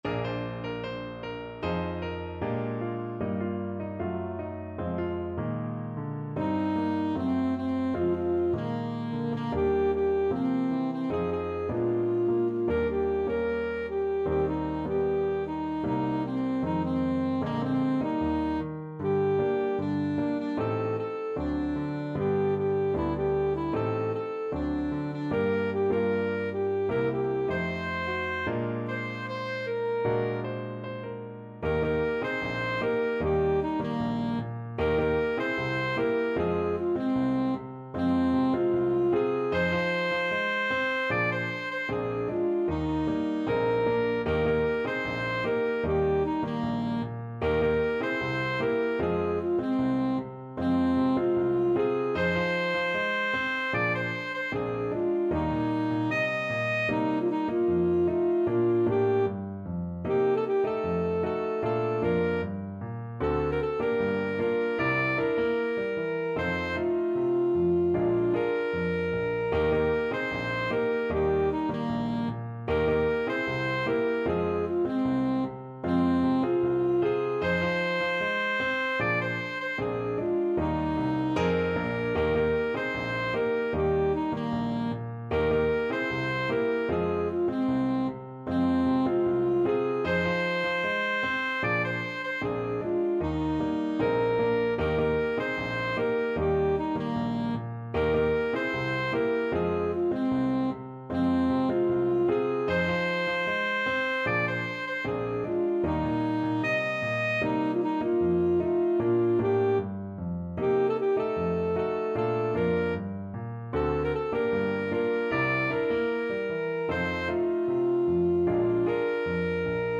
Alto Saxophone
2/2 (View more 2/2 Music)
Bb4-Eb6
Pop (View more Pop Saxophone Music)